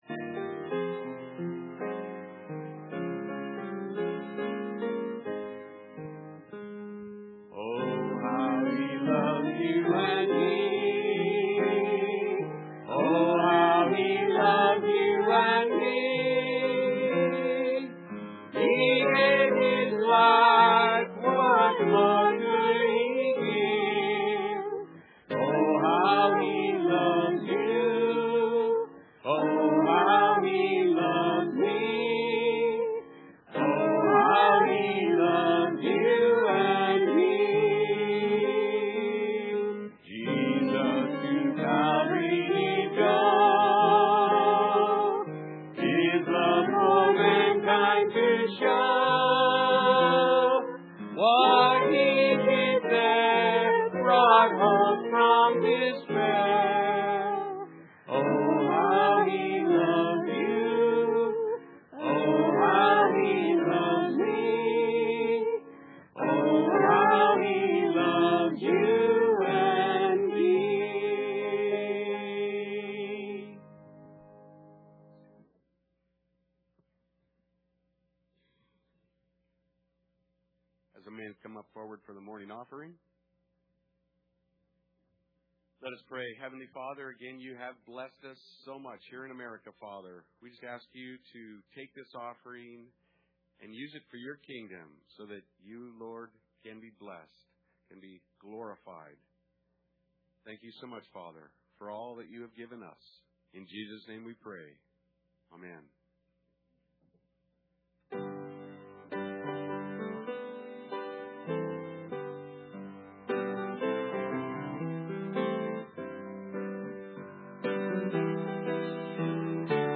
Passage: Ephesians 1:3-14 Service Type: Sunday Service